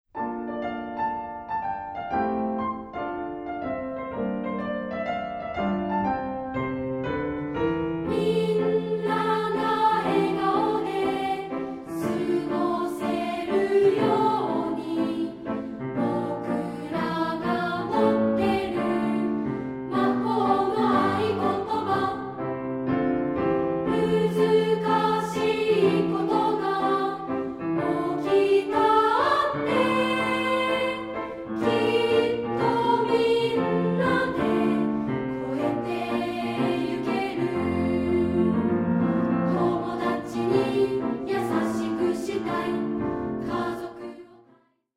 斉唱／伴奏：ピアノ